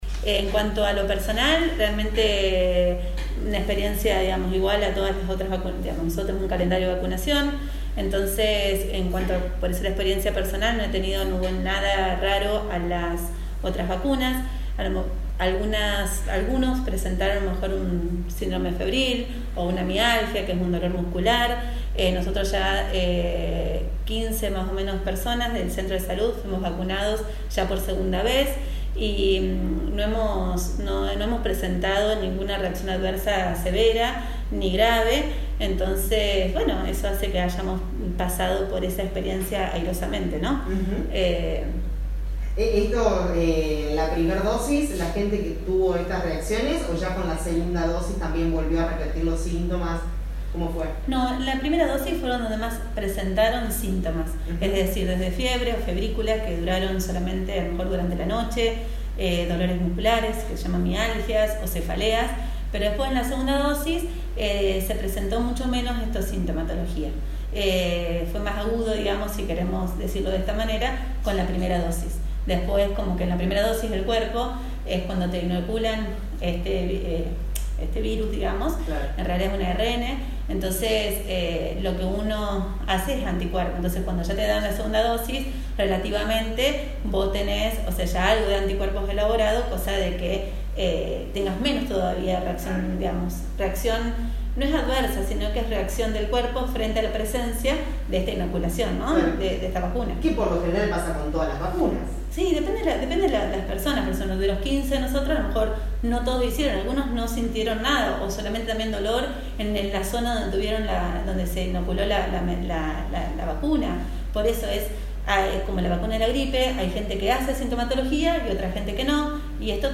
Profesionales del Centro de Salud hablaron de su experiencia con la Sputnik V, anticiparon testeos masivos y campaña de vacunación.